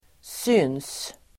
Uttal: [syn:s]